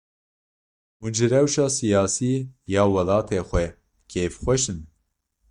Pronounced as (IPA)
/sɪjɑːˈsiː/